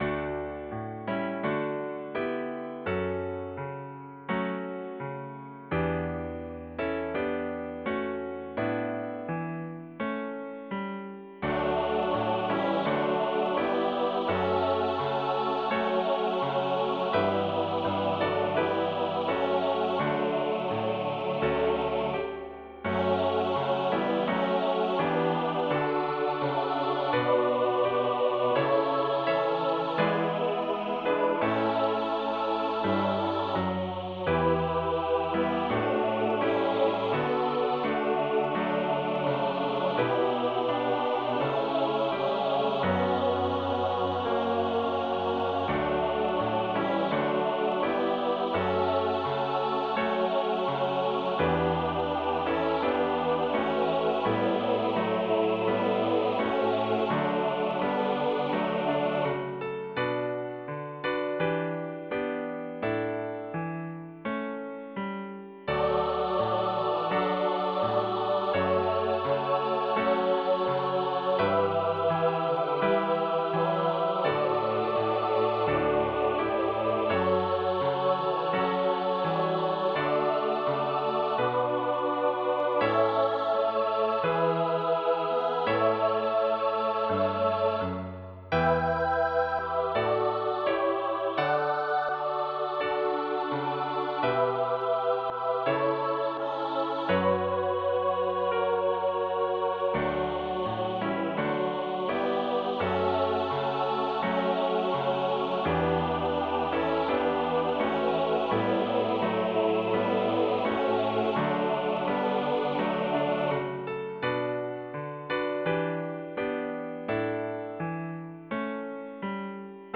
An original Christmas carol arranged for SSATB choir and piano.
A 2 page (1 sheet back to back) Voice Parts is included, also a computer generated sound file.